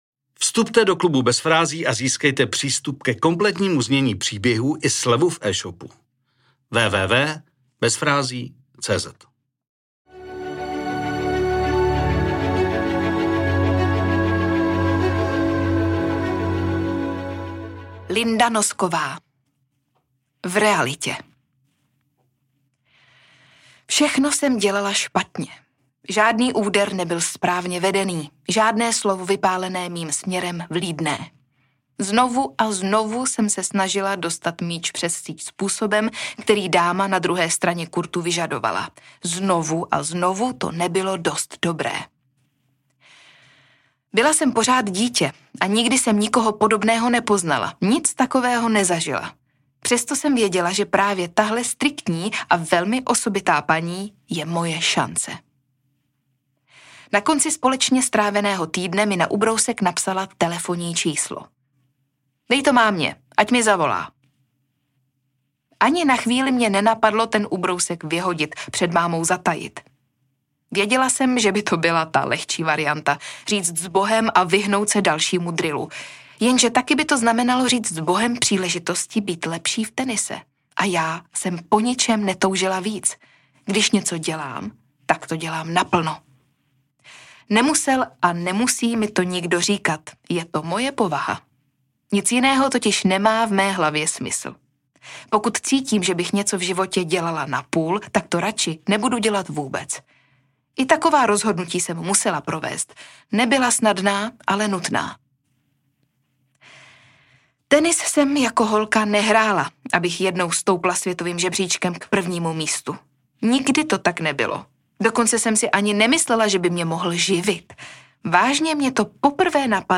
Celý příběh pro vás načetla skvělá Veronika Khek Kubařová .